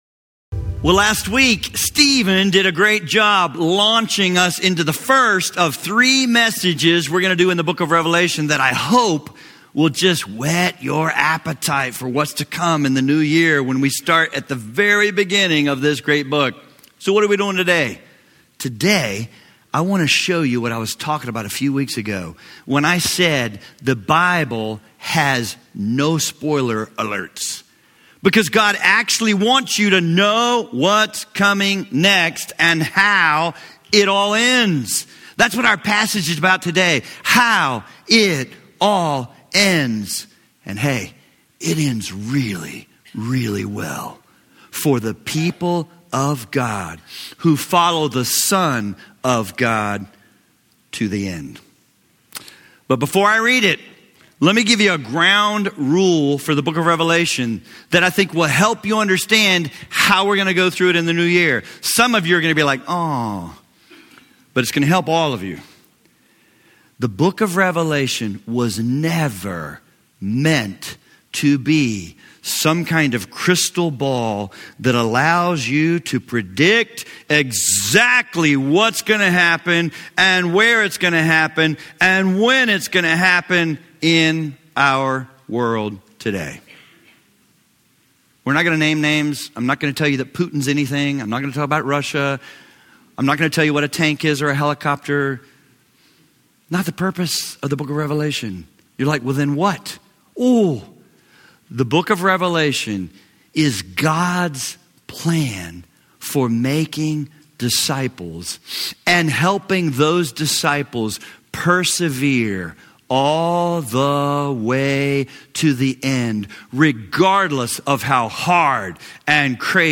Preaching and teaching from Grace Fellowship Church in Northern Kentucky